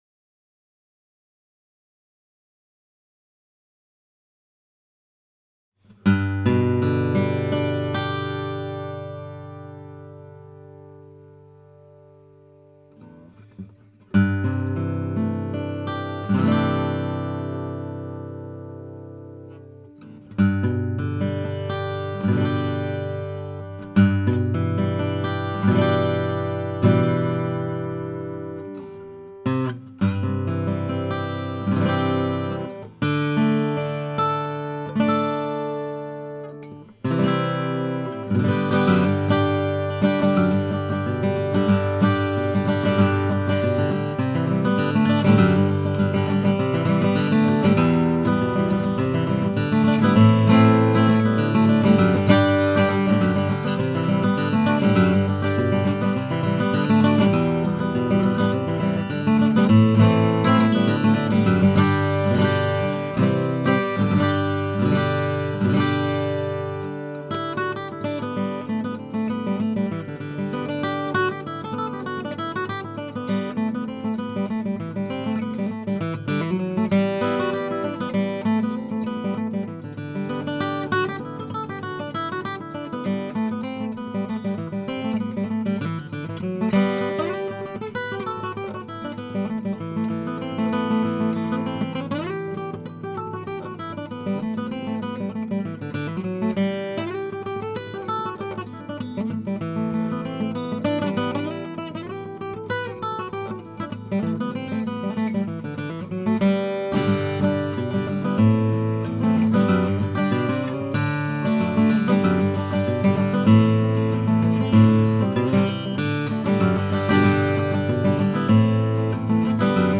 Many of these were made here in the shop about as simply as they could be done.
Flamed Maple with Rosewood Binding and Herringbone